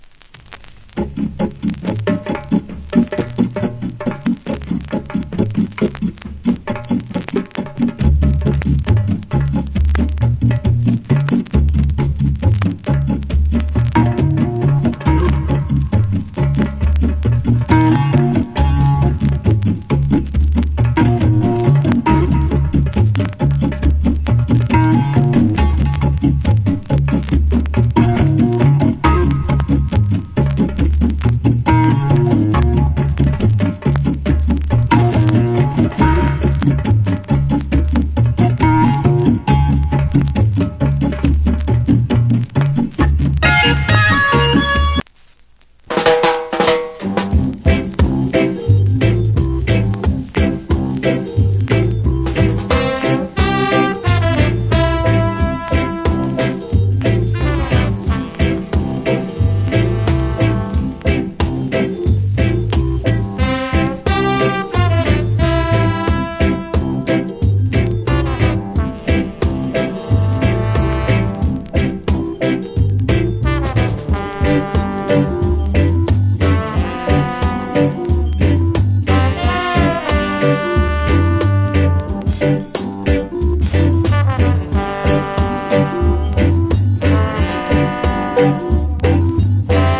Wicked Drum Reggae Inst.